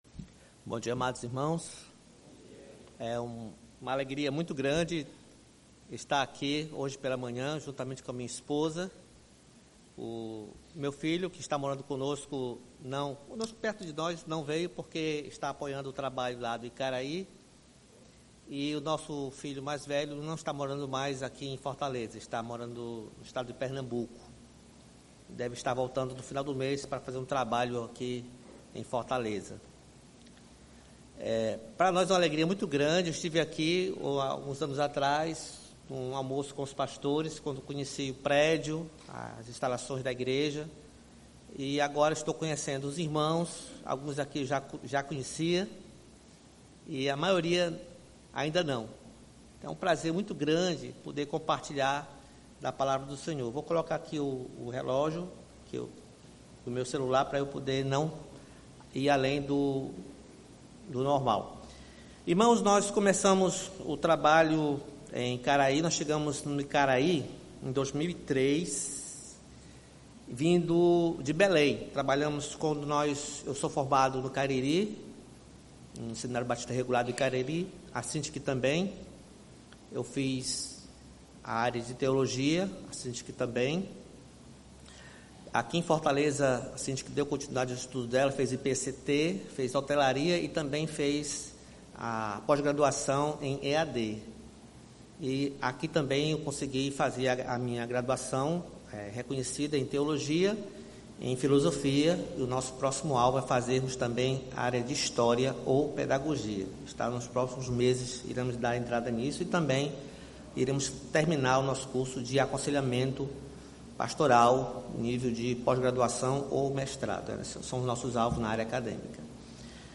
Igreja Batista Luz do Mundo, Fortaleza/CE.
pregação